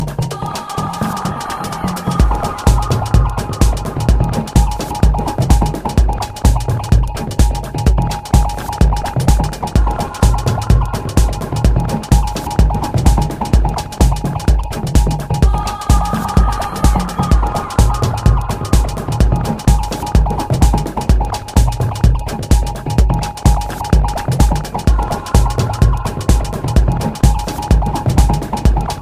Dance
techno , house , deep house , tech house